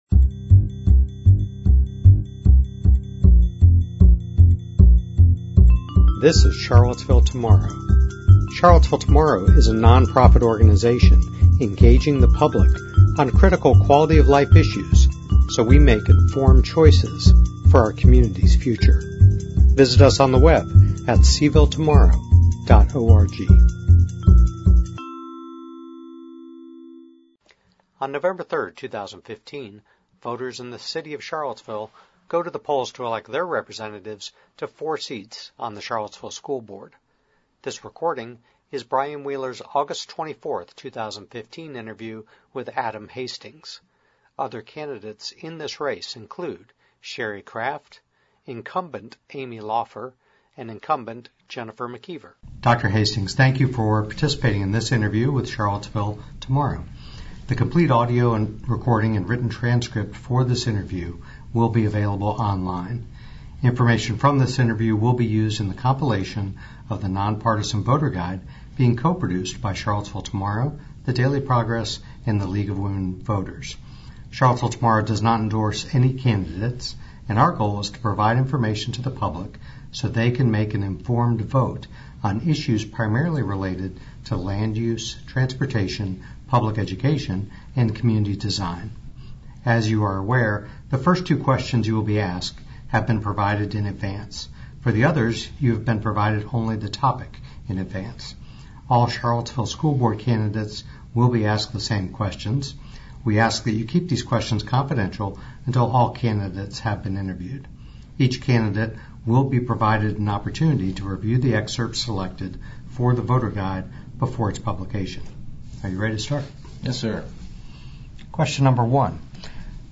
Candidate interview audio